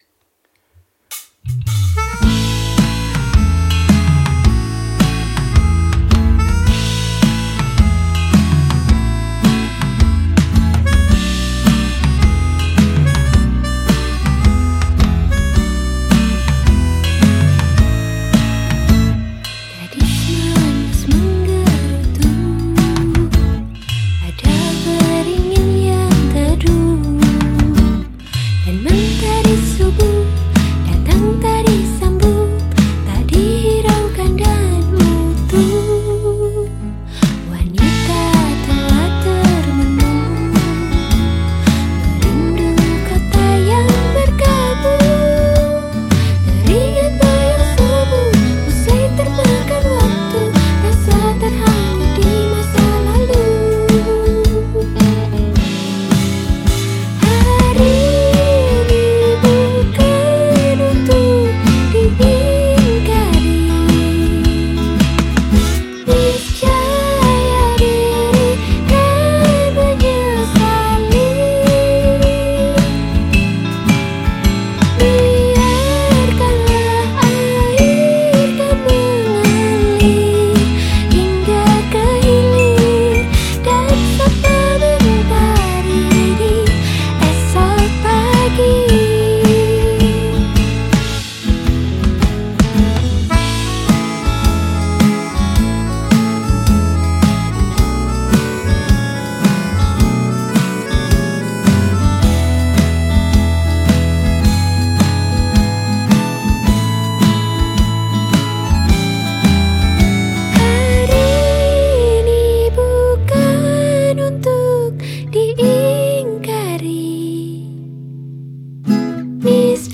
Malang Pop
Gitar
Keys
Bass
vokalis